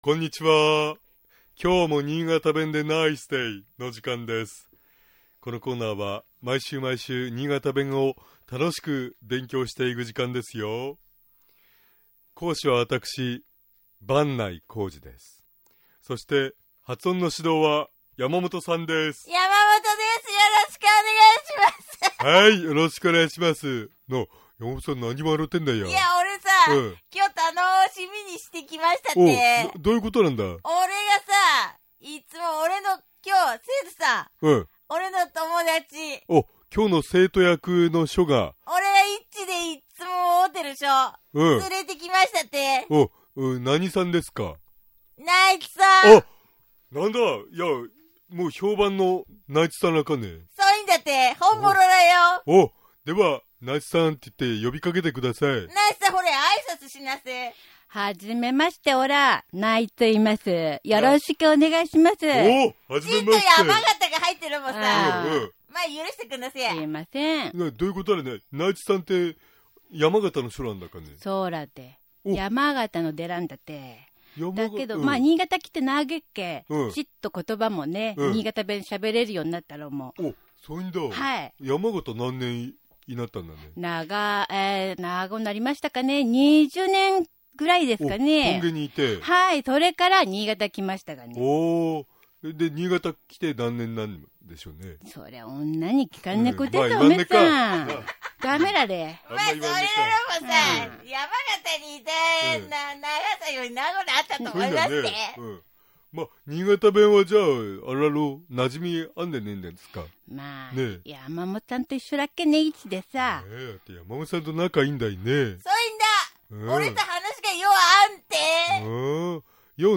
しかし、新潟では「～みたいな」ではなく「～みてな」という風に発音し、 「カエルみてなかっこう」などと表現します。
（スキット） （妻）ほれ、とうちゃん、見れて。
尚、このコーナーで紹介している言葉は、 主に新潟市とその周辺で使われている方言ですが、 それでも、世代や地域によって、 使い方、解釈、発音、アクセントなどに 微妙な違いがある事を御了承下さい。 120820niigataben.mp3 ポッドキャストは携帯ではサービスしていません。